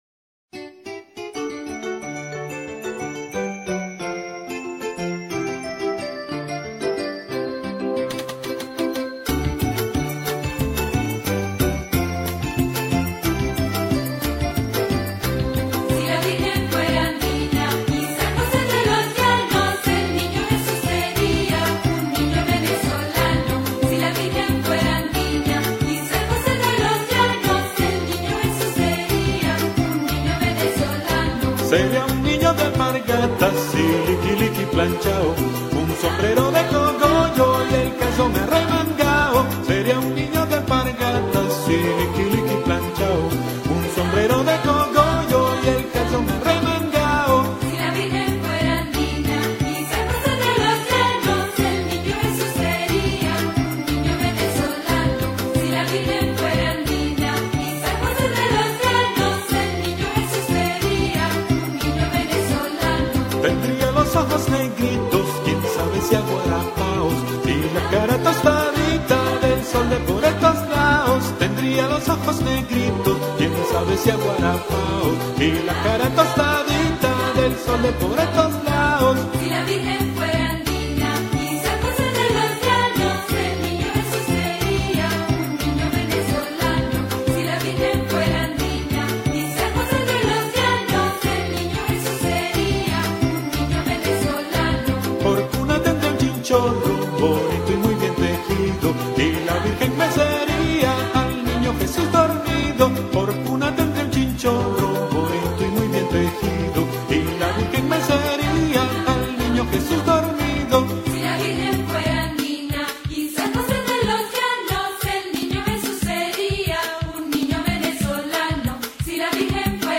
Volver a Podcast Ver todo Si Maria fuera andina on 2008-12-25 - Villancicos Descargar Otros archivos en esta entrada Nuestras Creencias Las creencias adventistas tienen el propósito de impregnar toda la vida.